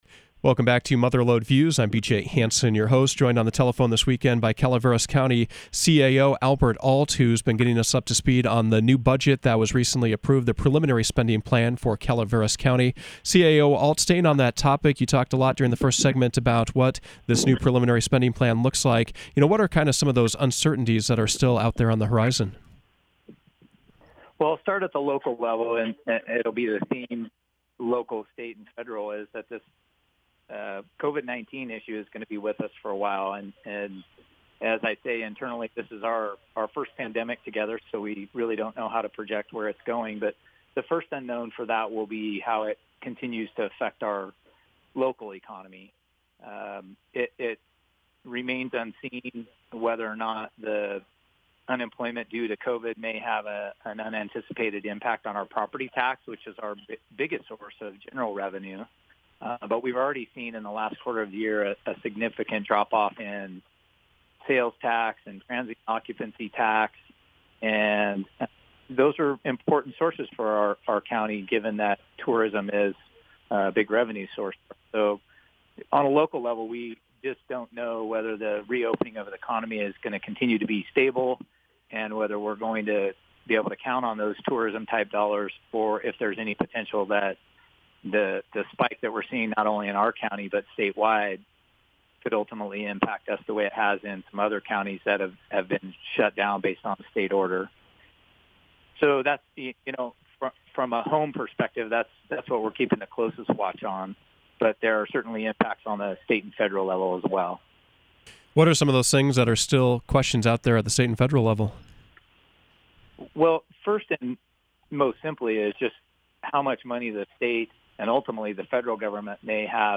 Mother Lode Views featured Calveras County CAO Albert Alt. He gave an overview of the new fiscal year budget and how it will impact government services. He also spoke about topics like the county’s efforts to regulate marijuana, and the local response to COVID-19.